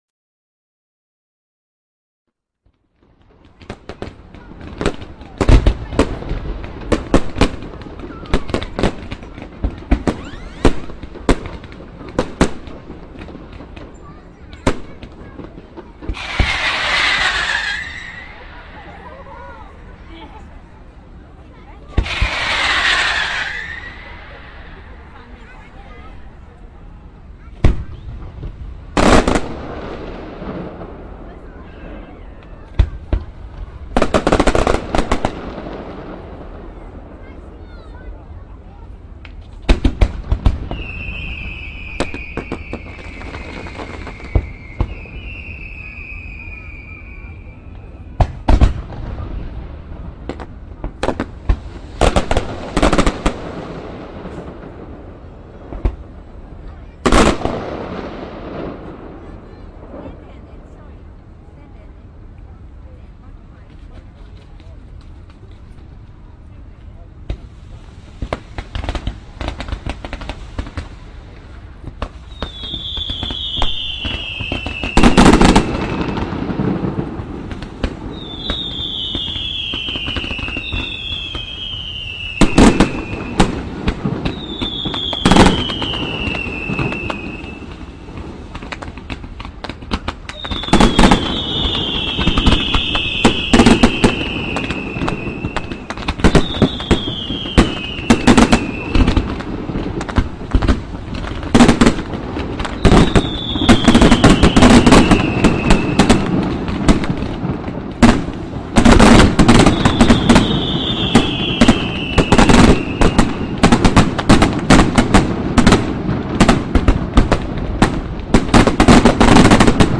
3D spatial surround sound "Fireworks"
3D Spatial Sounds